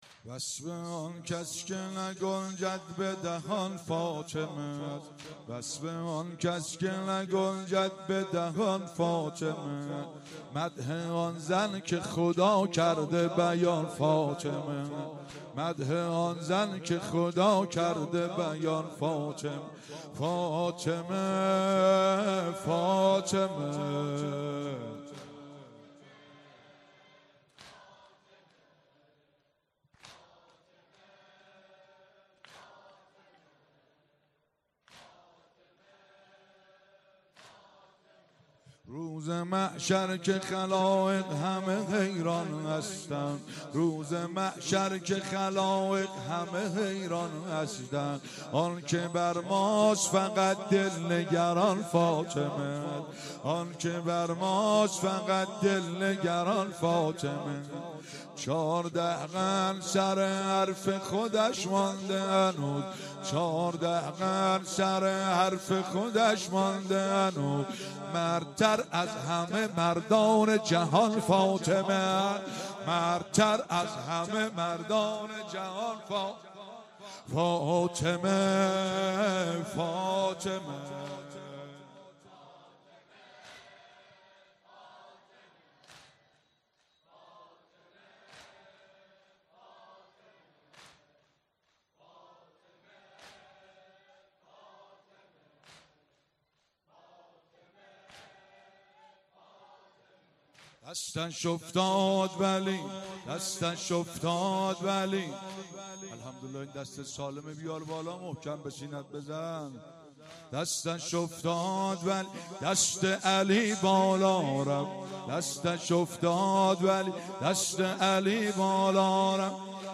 مراسم شب اول فاطمیه ۱۳۹۶
مداحی